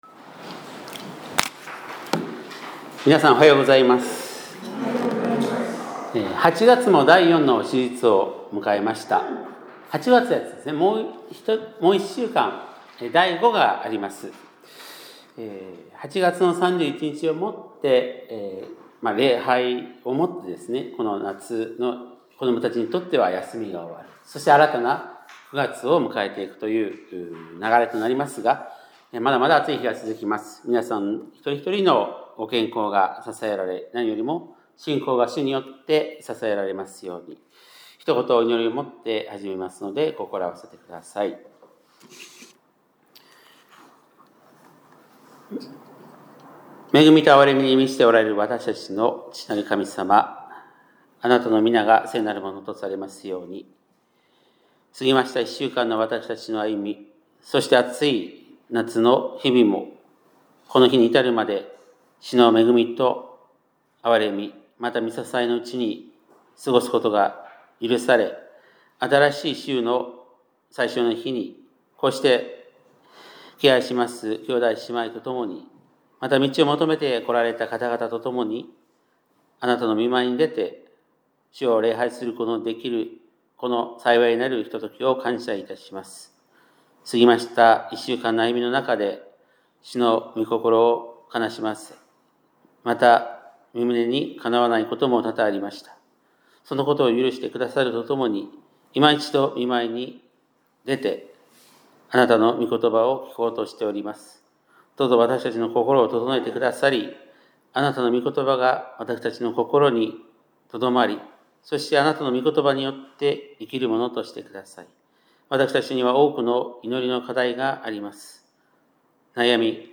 2025年8月24日（日）礼拝メッセージ